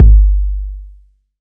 Kick
Original creative-commons licensed sounds for DJ's and music producers, recorded with high quality studio microphones.
Big Bass Drum Sample G Key 502.wav
roomy-kick-one-shot-g-key-506-Ktb.wav